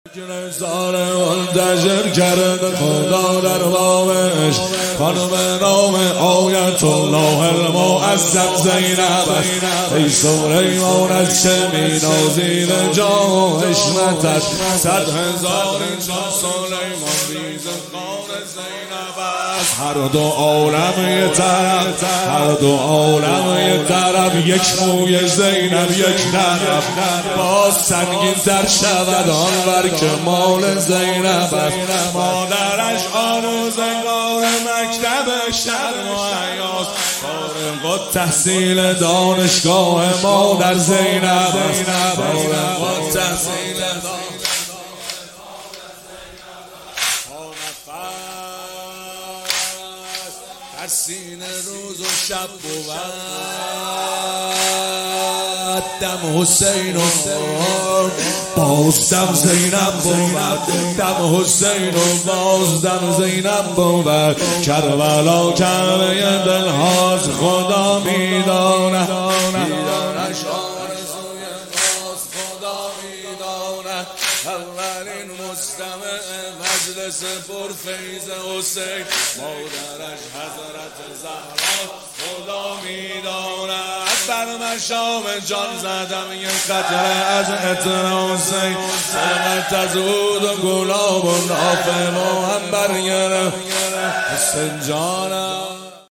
مداحی
شهادت امام صادق(ع) هیئت ام ابیها(س)قم